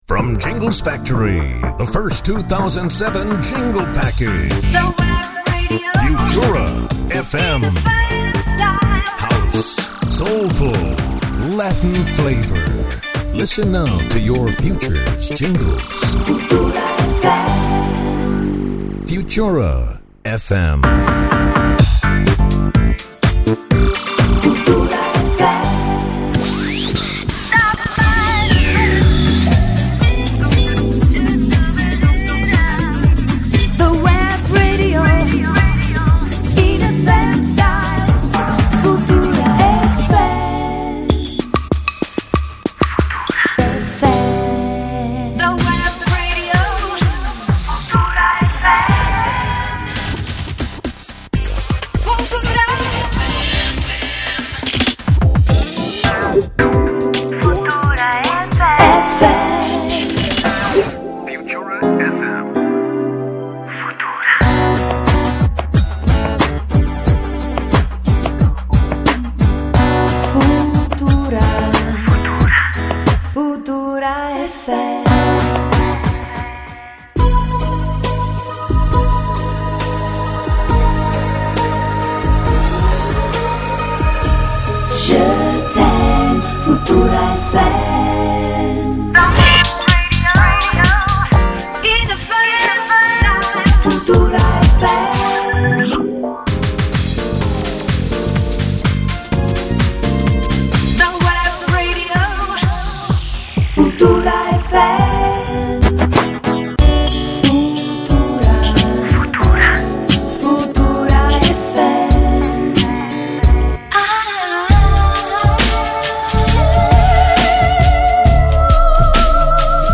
new jingles for 2007